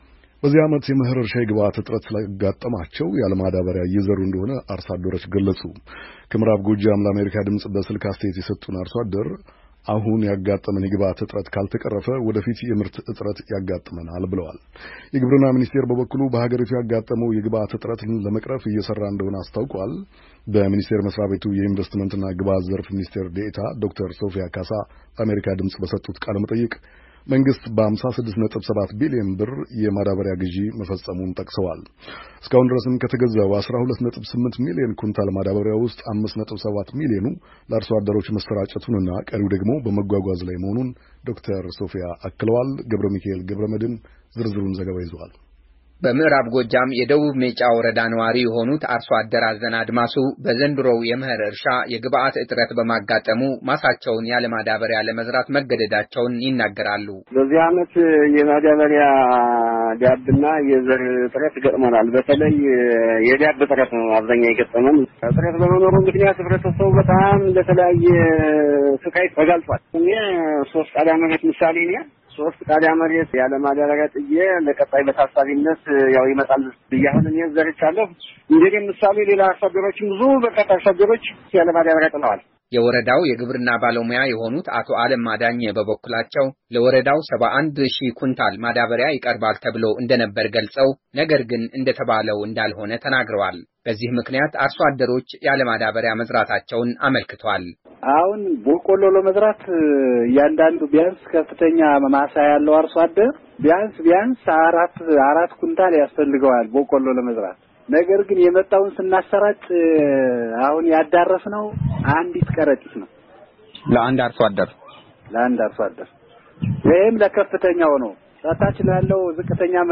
በዘንድሮው ዓመት የመኸር እርሻ፣ የግብአት እጥረት እንዳጋጠማቸውና ያለማዳበሪያ እየዘሩ እንደኾነ አርሶ አደሮች ሲናገሩ፤ ግብርና ሚኒስቴር በበኩሉ፣ ችግሩን ለመፍታት፣ የማዳበሪያ ግዥ እንደፈጸመና በተለይም፣ በሰኔ እና በሐምሌ ለሚዘሩ አርሶ አደሮች፣ በቂ ማዳበሪያ ስለሚቀርብ ስጋት ሊገባቸው አይገባም፤ ብሏል፡፡ ከዐማራ ክልል ምዕራብ ጎጃም ዞን ሜጫ ወረዳ፣ ለአሜሪካ ድምፅ በስልክ አስተያየት የሰጡ አርሶ አደር፣ የግብአት እጥረት በማጋጠሙ፣ ማሳቸውን ያለማዳበሪያ...